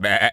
goat_baa_calm_07.wav